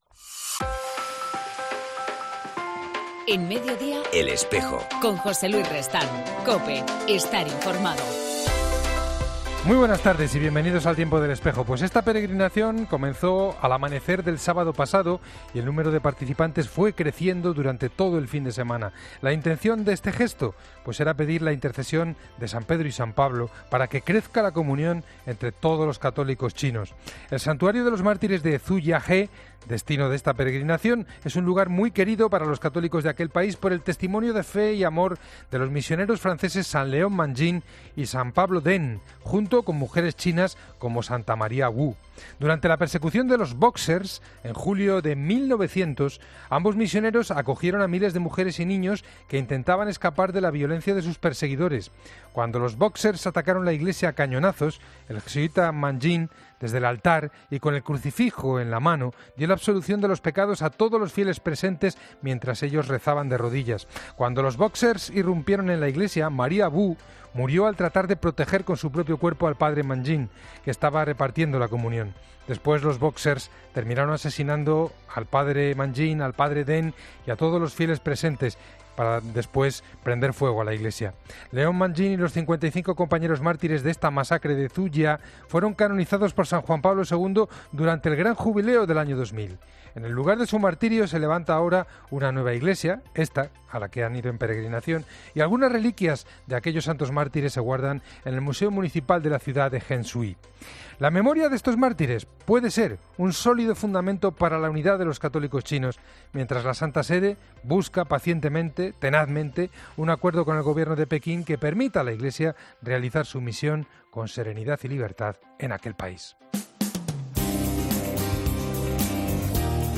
hablamos con la periodista